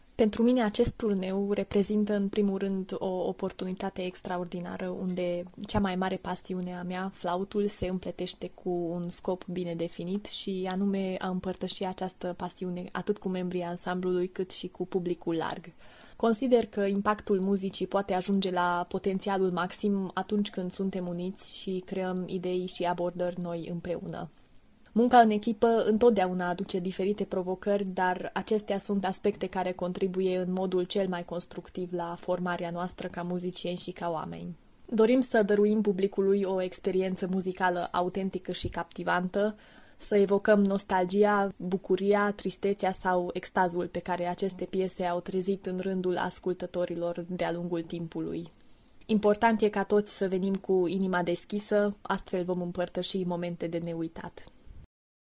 Interviuri cu protagoniștii proiectului - Radio România Timișoara